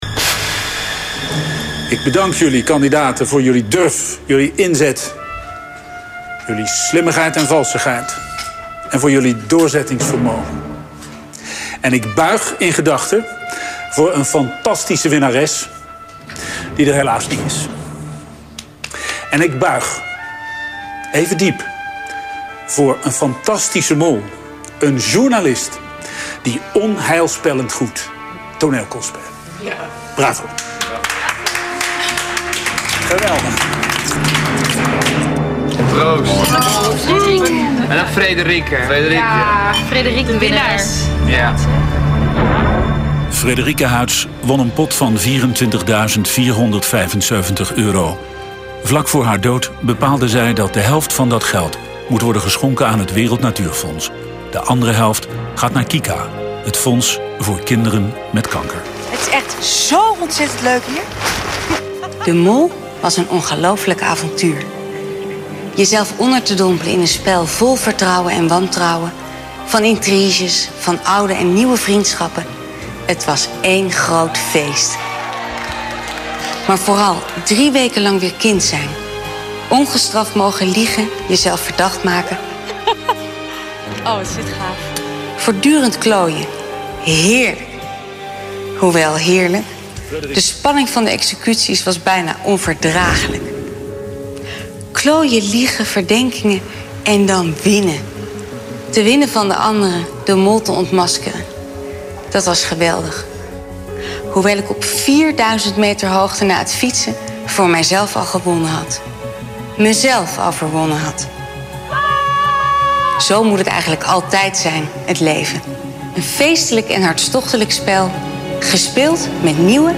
Ze klonk inderdaad geemotioneerd, maar ook trots, vooral toen ze vertelde dat ze zichzelf had overwonnen op die berg!
Toen ik naar de terugbleek keek, hoorde ik het in eerste instantie niet, maar haar stem klinkt daar dus geemotioneerd.
Ik heb van het eind van de finale een mp3-tje gemaakt inclusief de woorden van Frédérique.